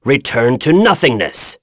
Lusikiel-Vox_Skill1.wav